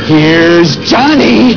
Ax blows and one-liner fly as Jack chops his way towards the film’s chilling conclusion.